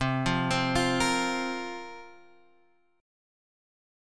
C7sus4 chord